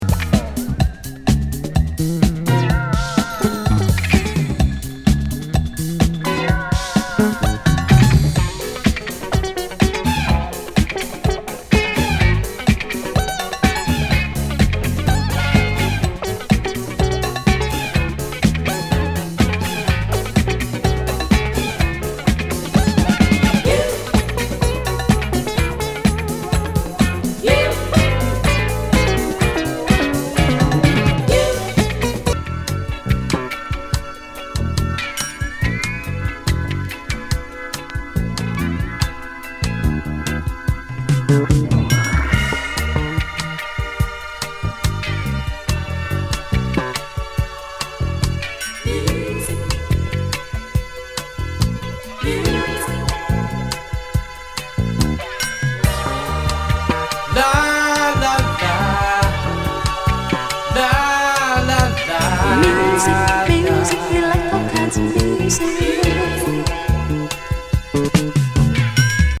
スペーシー・ディスコ
激メロウ・スロー・ブラックネス